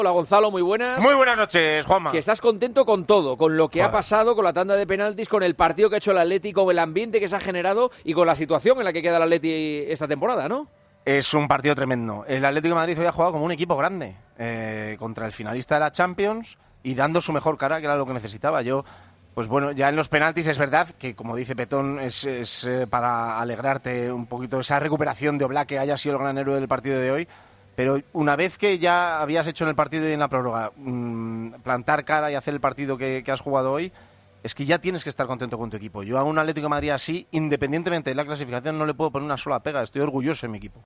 AUDIO: El comentarista de El Partidazo de COPE elogió el gran partido del Atlético de Madrid y su clasificación para la siguiente ronda de la Champions League.